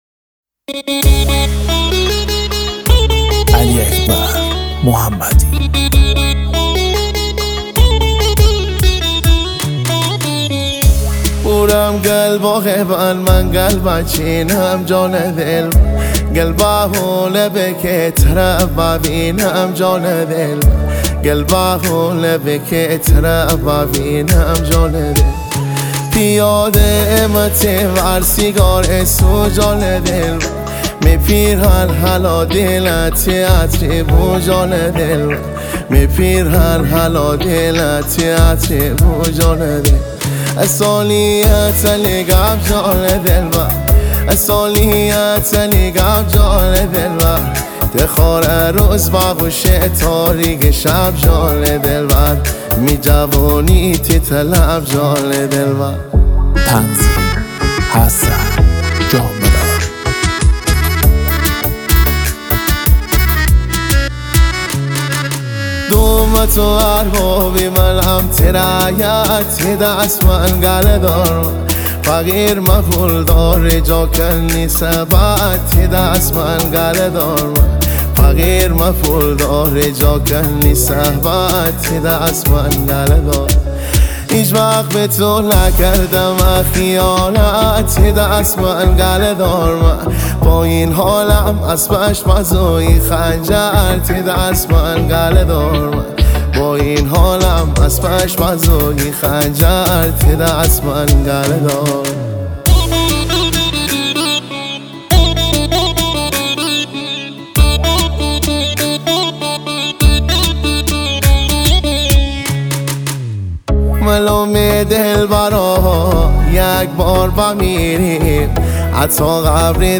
با سبک ریمیکس مازندرانی